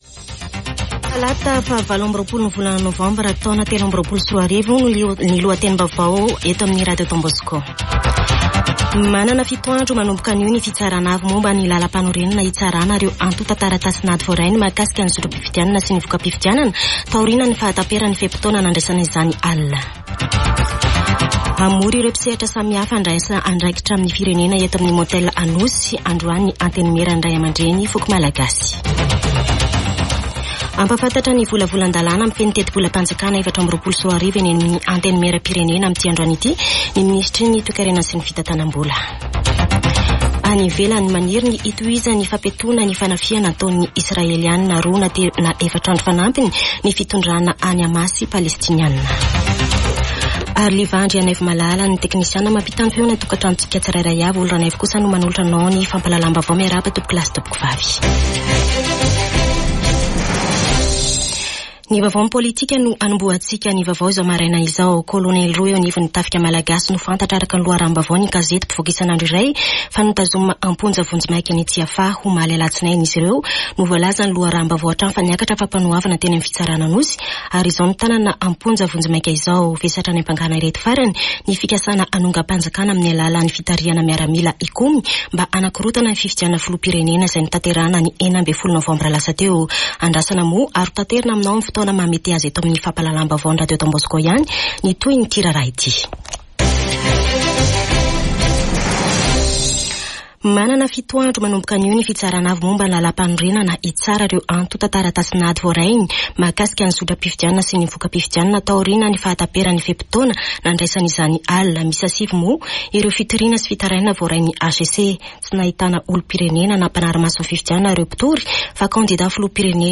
[Vaovao maraina] Talata 28 nôvambra 2023